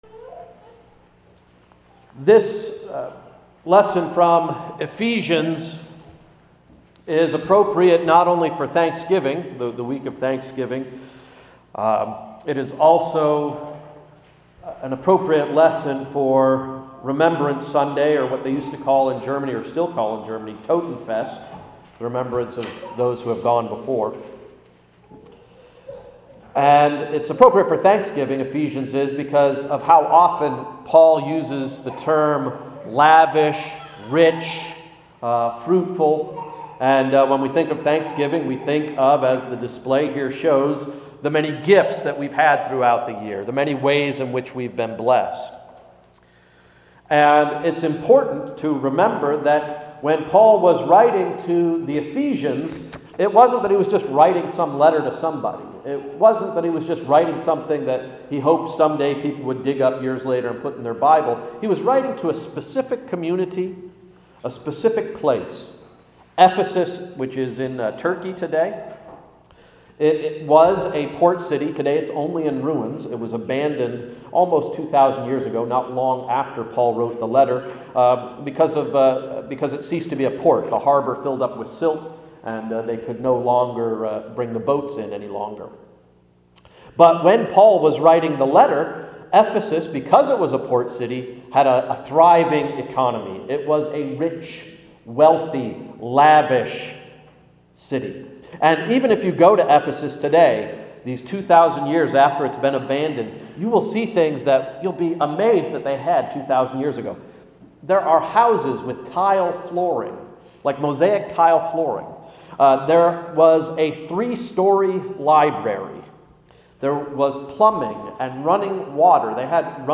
Sermon of November 20, 2011–“Embarassment of Riches”